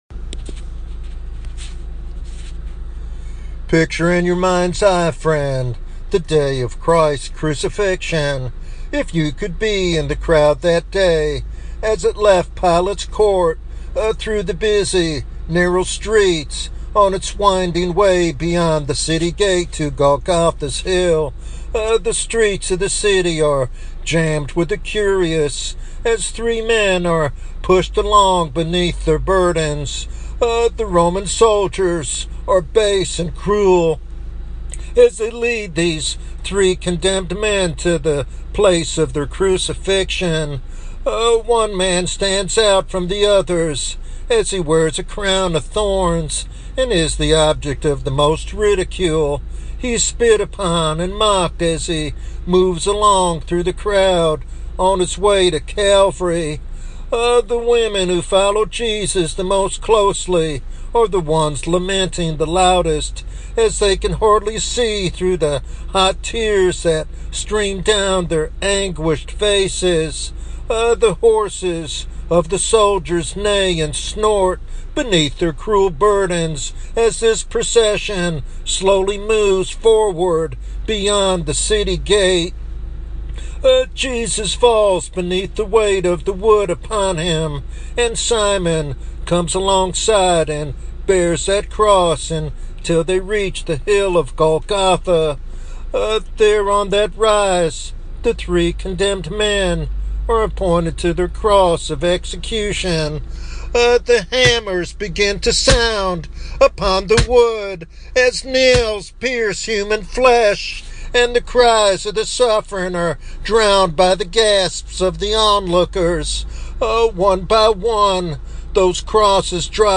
The sermon draws deeply from Scripture, especially Isaiah 53, to reveal the significance of Christ's death for sinners.